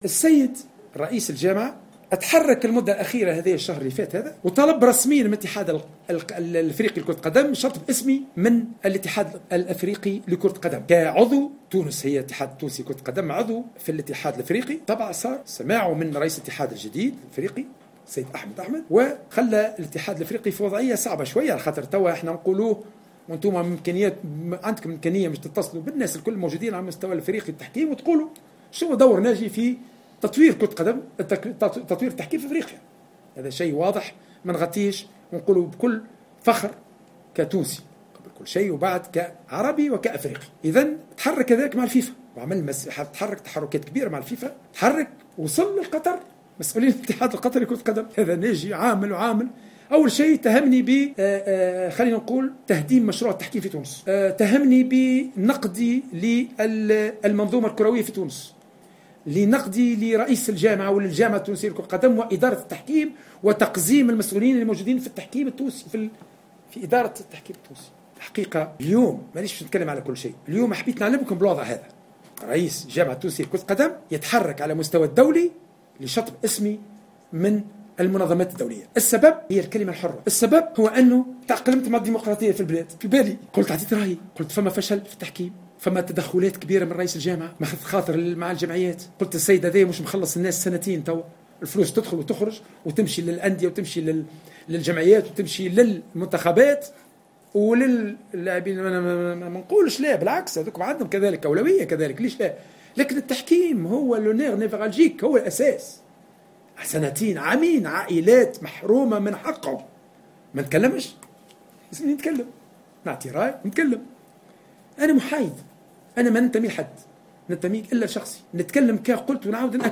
في ندوة صحفية